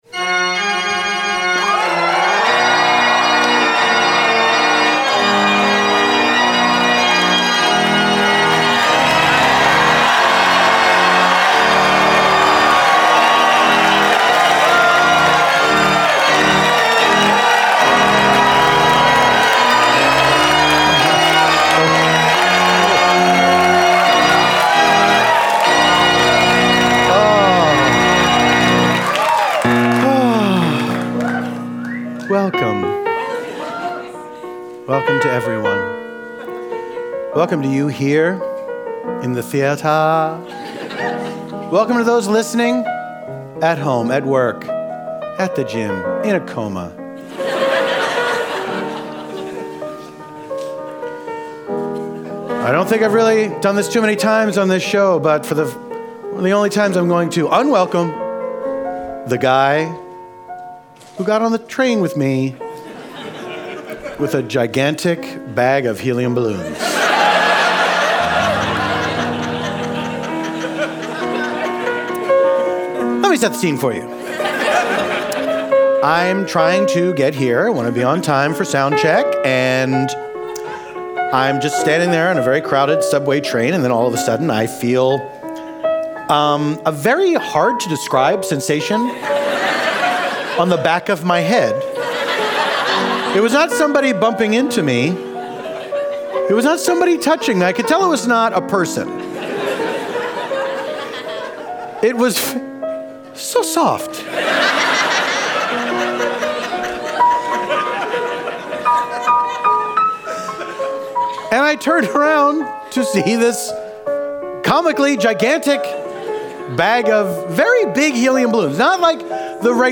Then, they are joined by improvisers Eugene Cordero and Tawny Newsome, to improvise a story set at a Parking Lot at a Phish Concert.